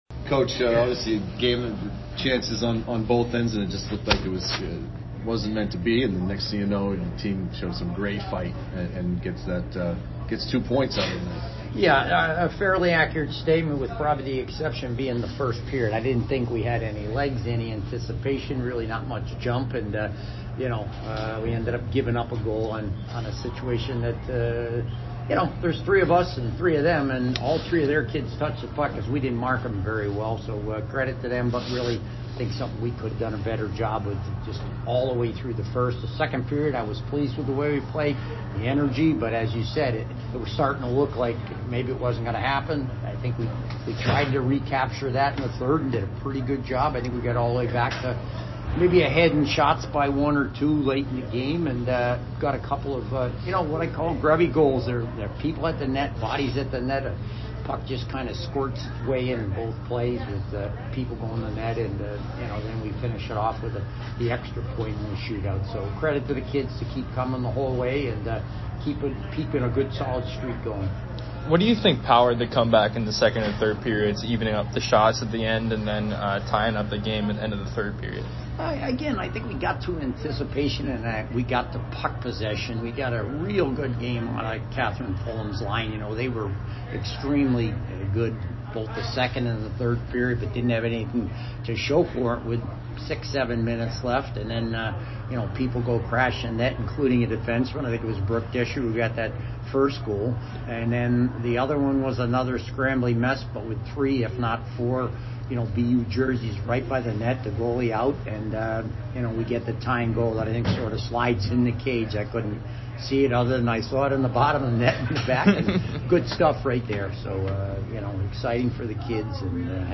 UConn Postgame Interview